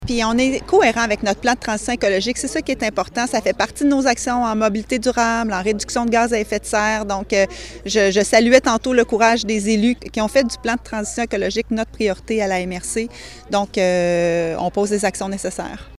Écouter la préfète de la MRC de Nicolet-Yamaska, Geneviève Dubois :